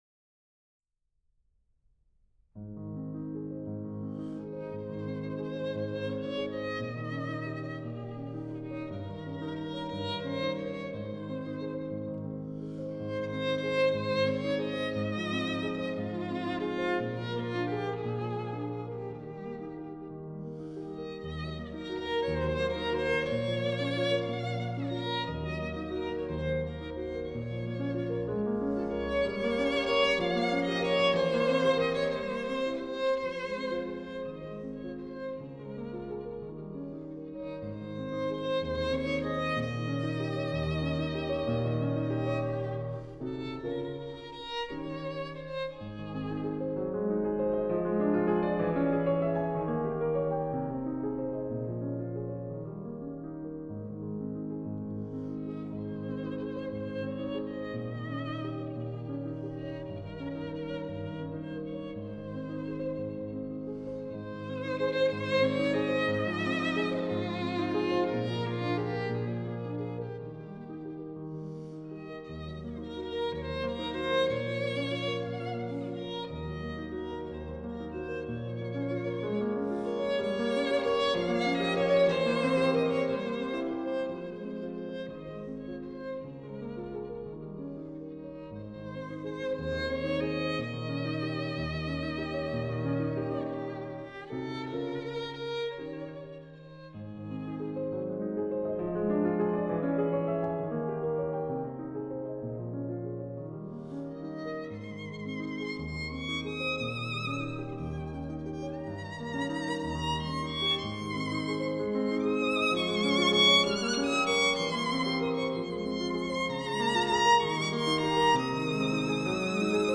arr. for violin and piano